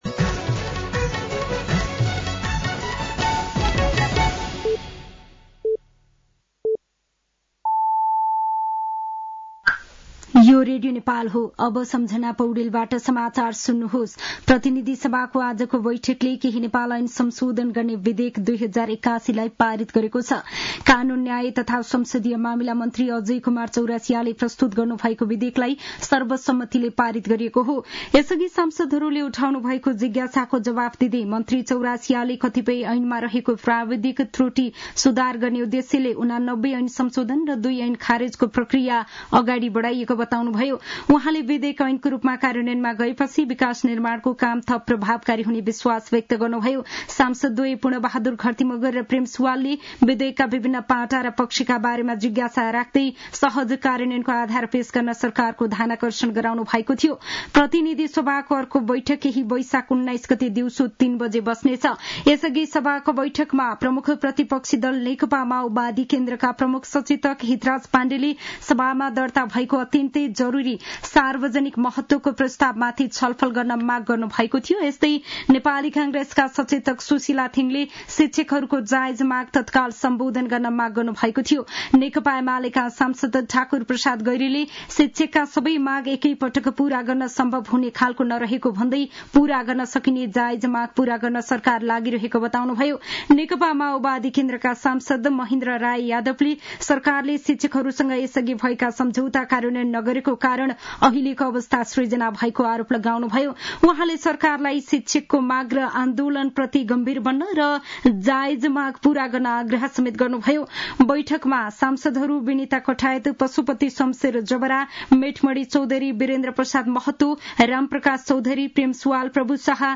साँझ ५ बजेको नेपाली समाचार : १६ वैशाख , २०८२